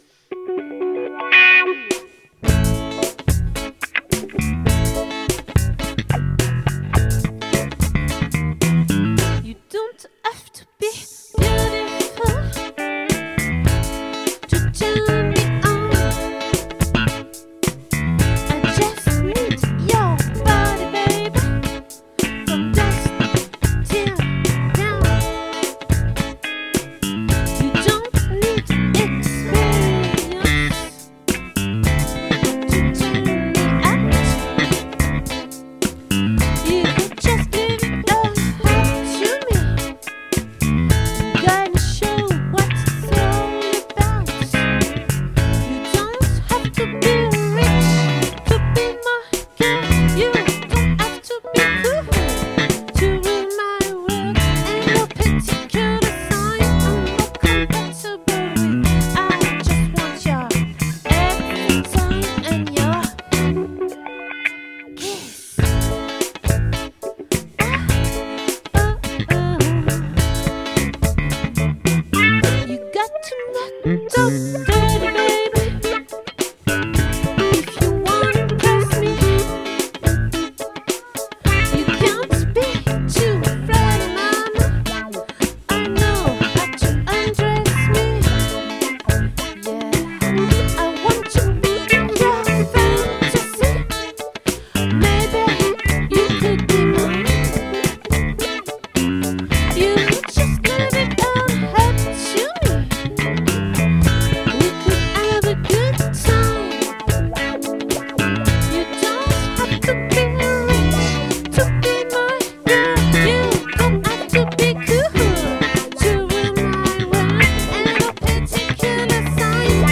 🏠 Accueil Repetitions Records_2022_02_17